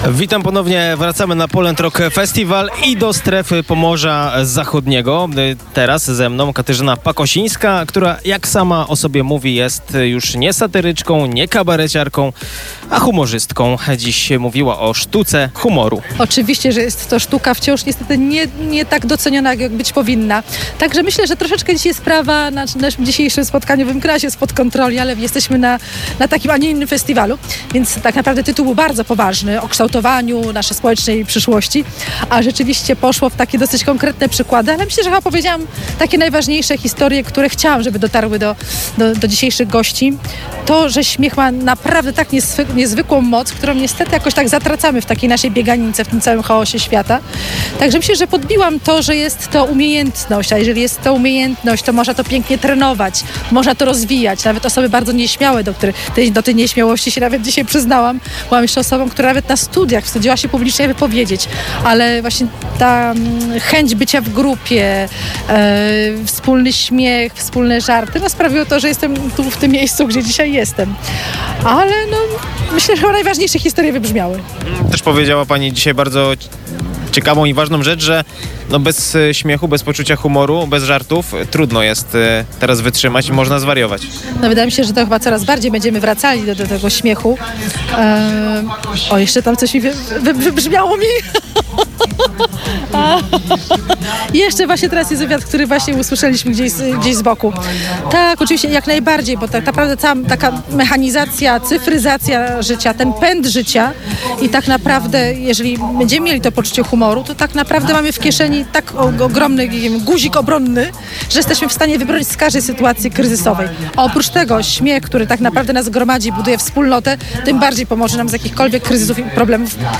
Publikujemy wywiady, nagrane przez reporterów Twojego Radia bezpośrednio w Strefie Pomorza Zachodniego.